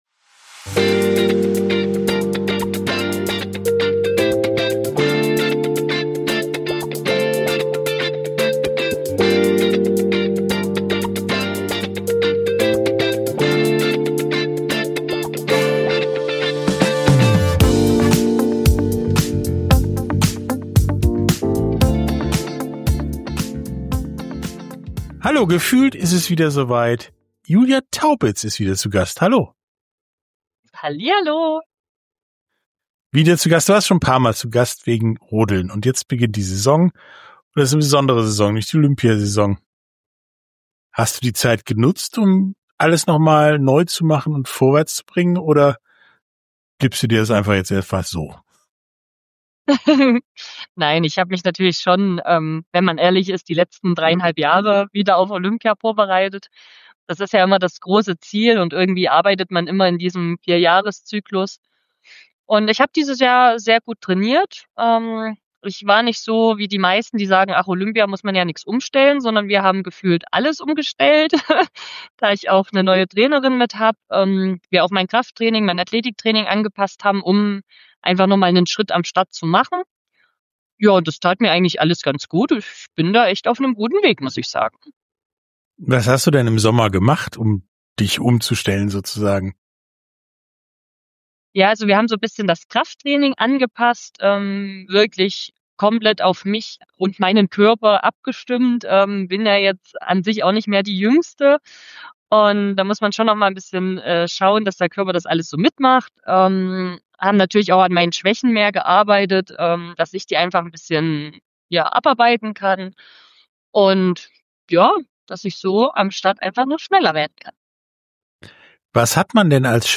Sportstunde - Interview mit Julia Taubitz – Auf dem Weg nach Cortina ~ Sportstunde - Interviews in voller Länge Podcast
sportstunde_julia_taubitz-_rennrodlerin.mp3